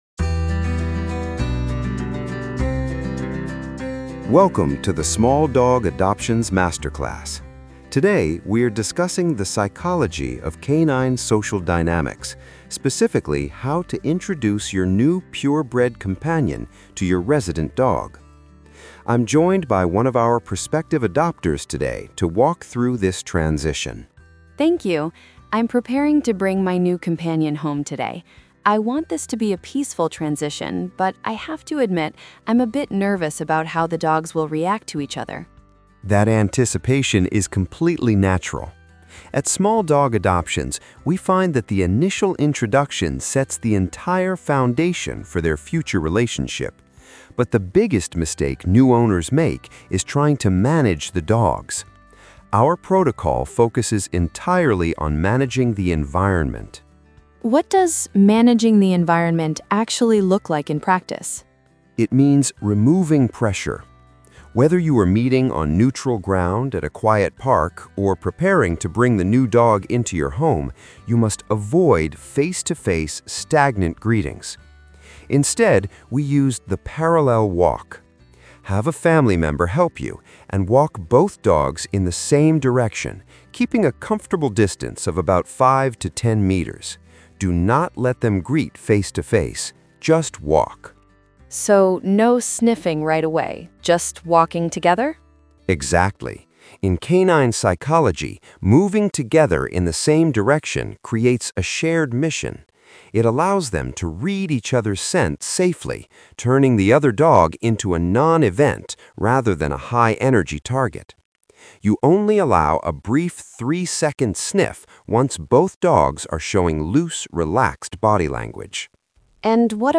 🎧 Audio Masterclass:
In this session, our specialists explain why managing the environment—not controlling the dogs—is the defining factor in long-term harmony.